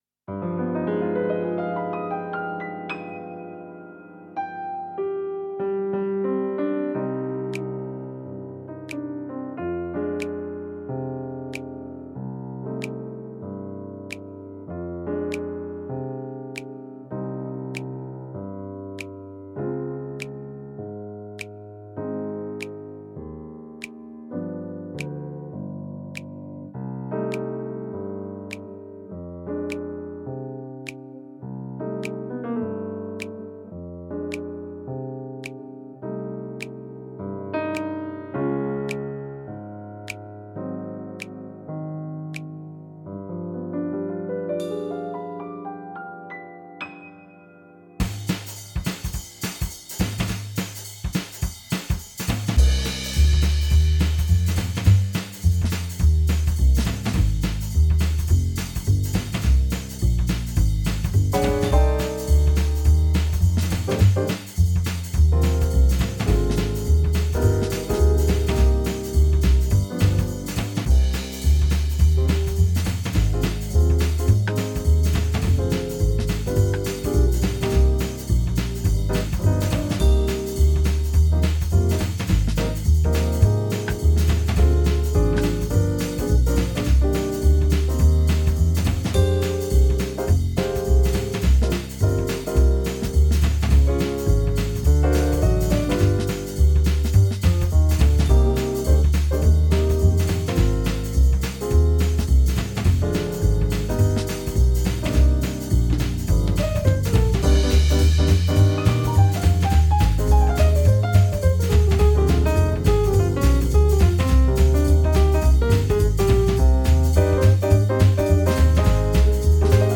Runterladen (Mit rechter Maustaste anklicken, Menübefehl auswählen)   Bei mir bistu shein (Playback)
Bei_mir_bistu_shein__5_Playback.mp3